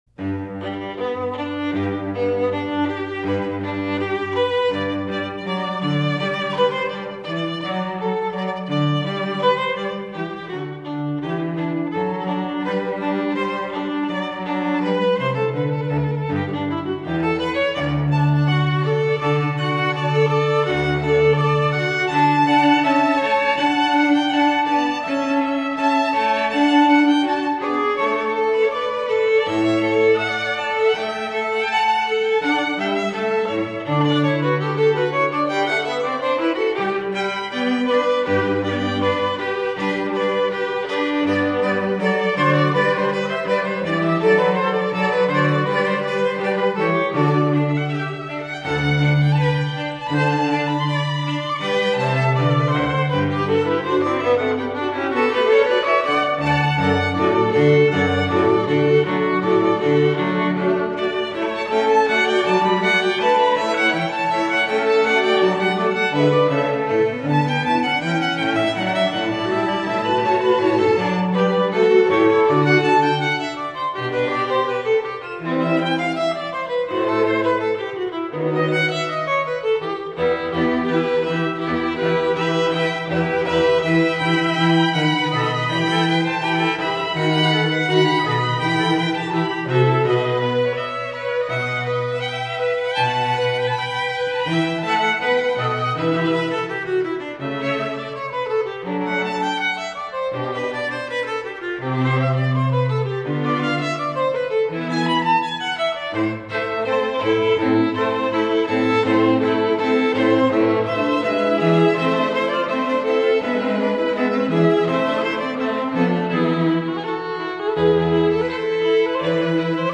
Voicing: 4 Strings